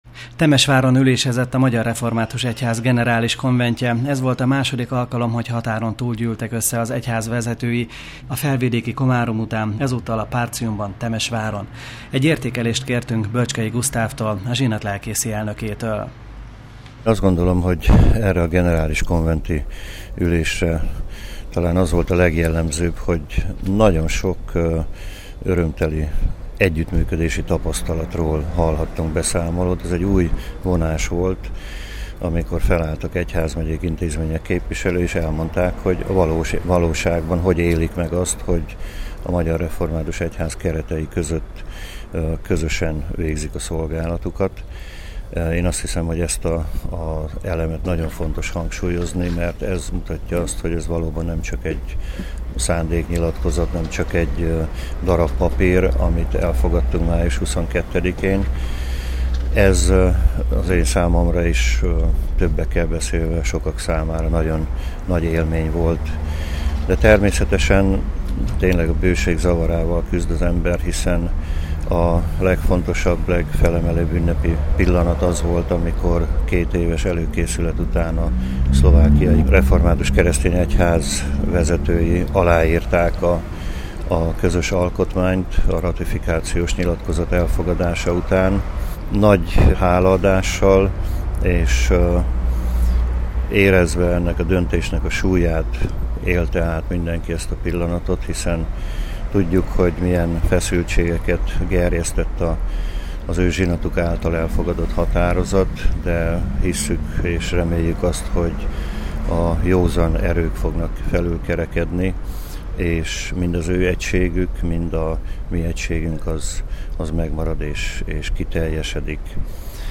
Fényképeket és rádiós riportokat nézhetnek meg illetve hallgathatnak meg a Generális Konvent június 21-22-ei plenáris üléséről.